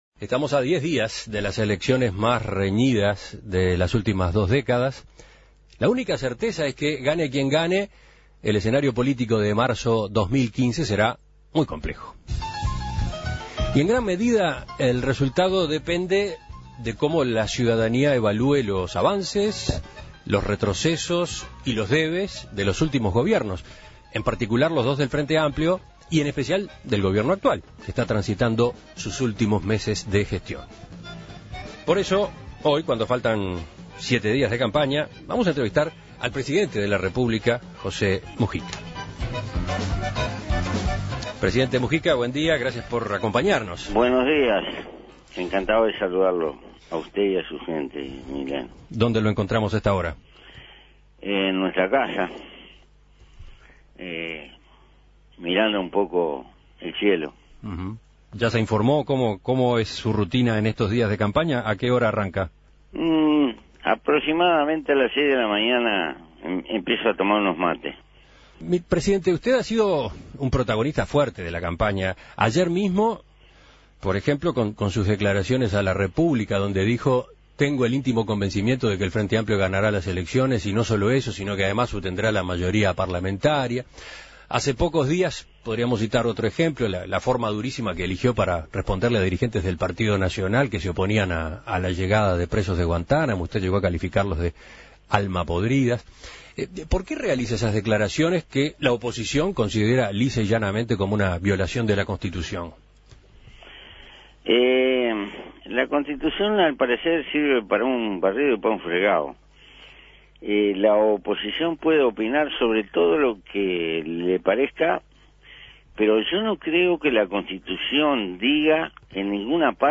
La oposición lo ha acusado de violar la Constitución, pero el primer mandatario niega que esto sea así. En la recta final, y a poco de terminar su mandato, En Perspectiva dialogó con el presidente para hacer un balance de su gestión, hablar de su rol en la campaña y su futuro político.